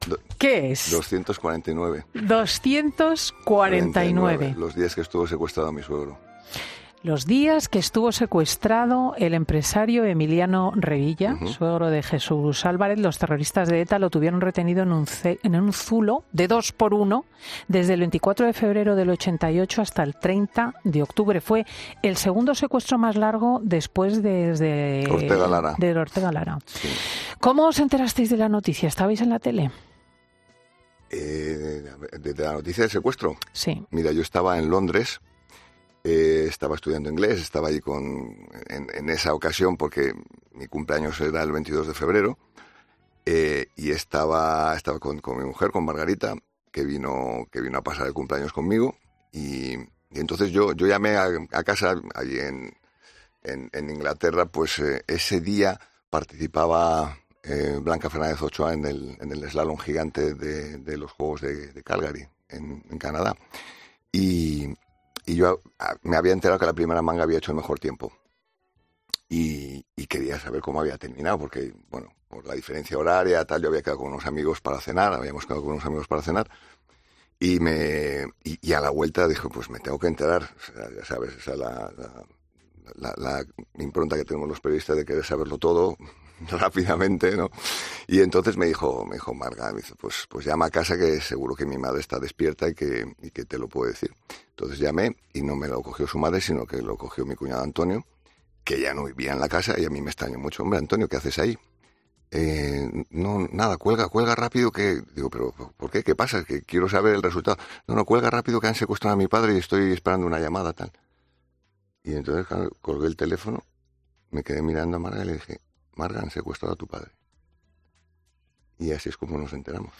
El célebre periodista ha estado esta mañana en 'Fin de Semana' con Cristina López Schlichting y ha repasado uno de los momentos más difíciles de su vida
Y es que Cristina López Schlichting decidía hacerle una entrevista a modo de juego: con un bombo que sacaba fechas clave en las que nos tenía que contar por qué eran momentos que habían marcado su vida.